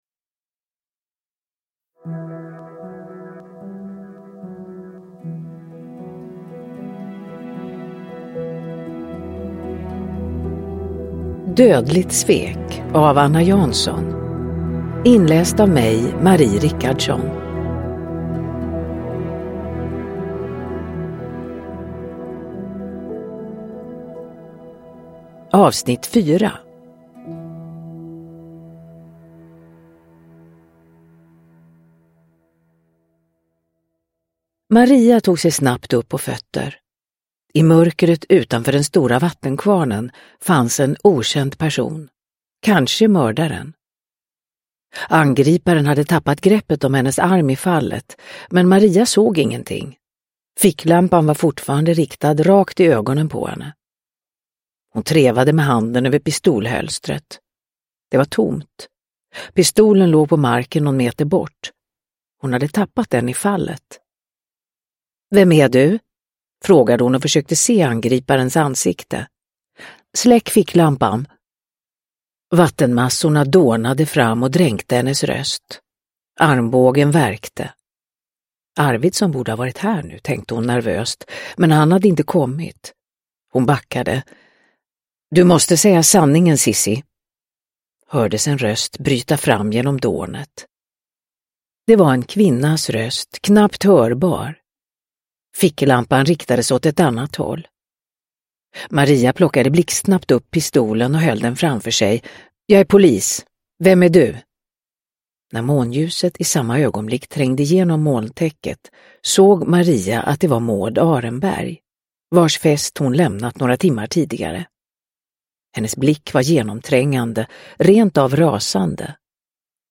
Dödligt svek - 4 – Ljudbok – Laddas ner
Uppläsare: Marie Richardson